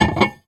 R - Foley 30.wav